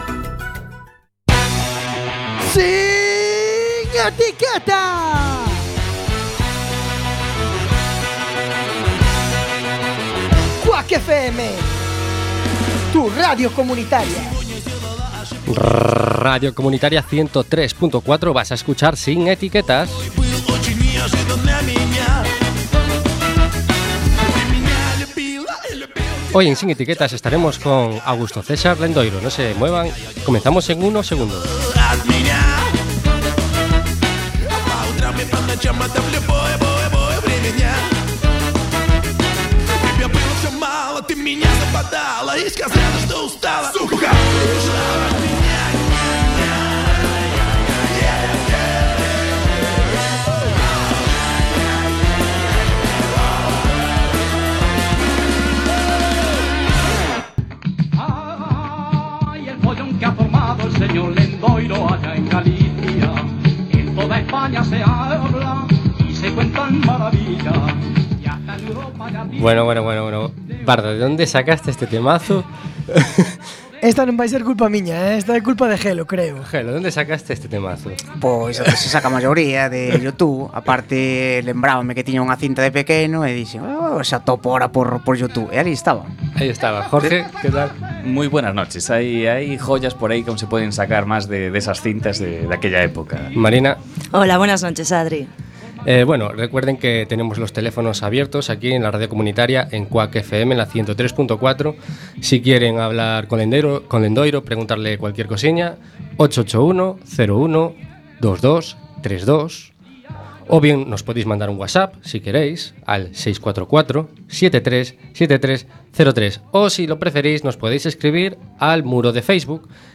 Magazine con entrevistas distendidas y frescas.